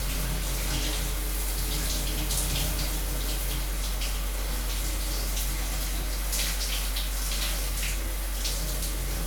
water_running_shower_dripping_loop_04.wav